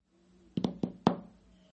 描述：步骤，只是，步骤